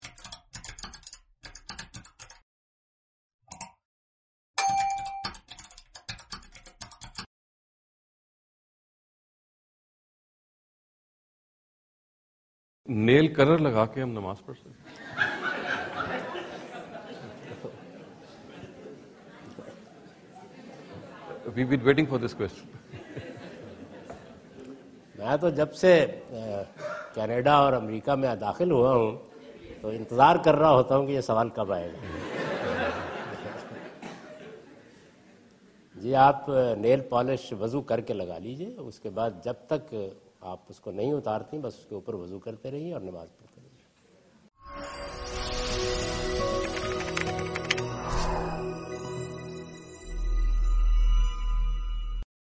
Javed Ahmad Ghamidi answer the question about "Offering Prayer with Nail Polish" during his visit to Georgetown (Washington, D.C. USA) May 2015.
جاوید احمد غامدی اپنے دورہ امریکہ کے دوران جارج ٹاون میں "نیل پالش لگا کر نماز پڑھنا" سے متعلق ایک سوال کا جواب دے رہے ہیں۔